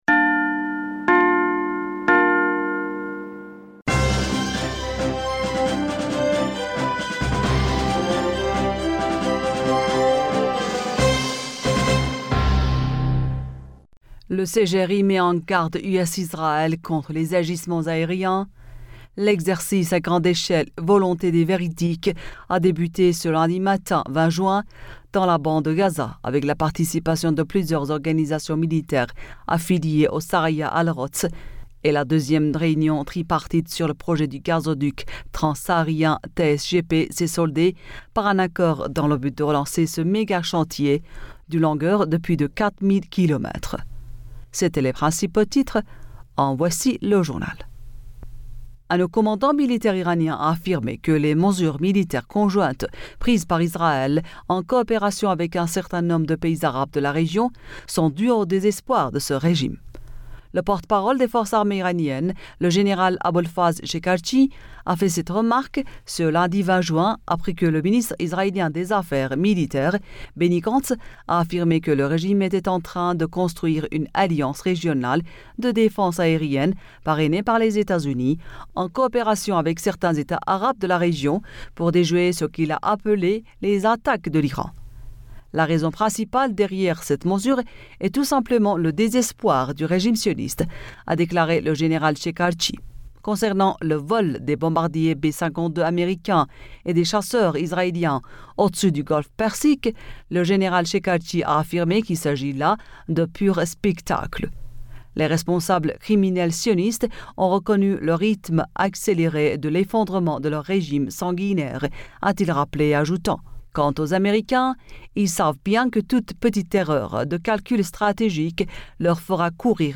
Bulletin d'information Du 21 Juin